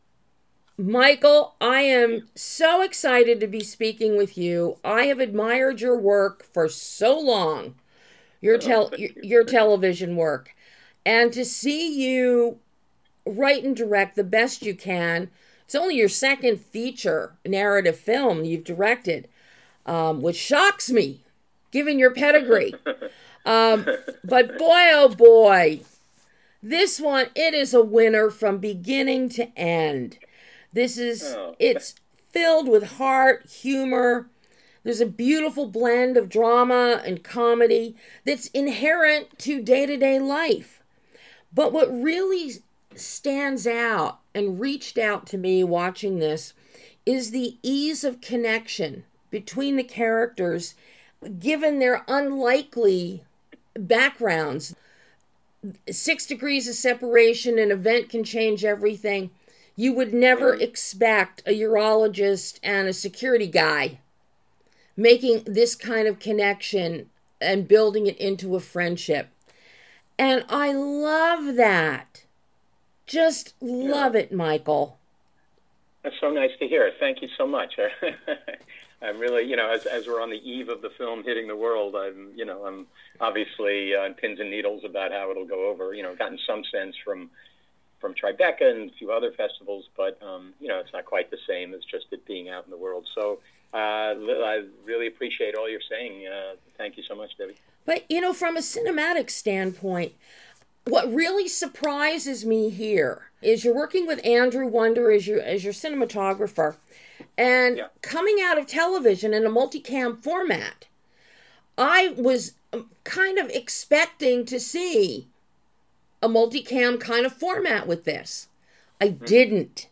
Writer/Director MICHAEL J. WEITHORN delivers a beautiful, funny, and wonderfully warm film with THE BEST YOU CAN - Exclusive Interview
It was a privilege and joy to speak with writer/director MICHAEL J. WEITHORN and to do so about his new narrative feature film, THE BEST YOU CAN.